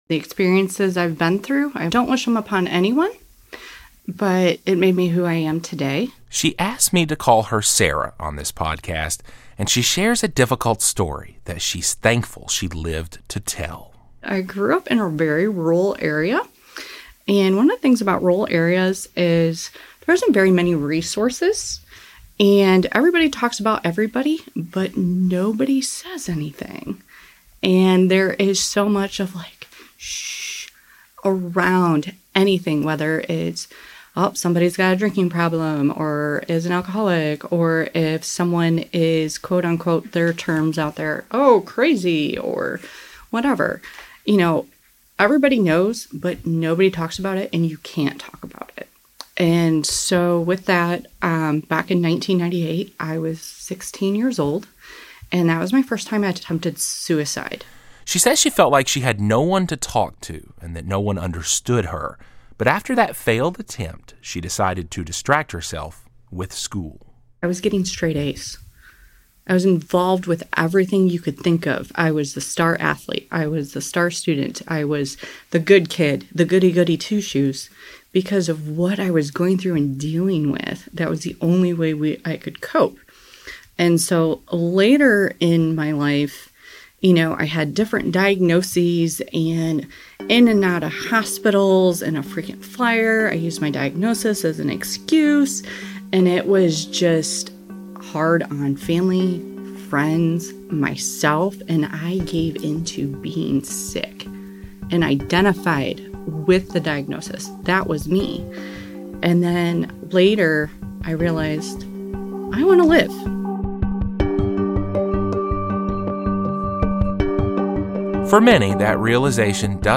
Mental health experts discuss the reasons behind the common intersection of behavioral health and the criminal justice system, the tools available to local people struggling with mental health issues, and how to help a loved one without making matters worse.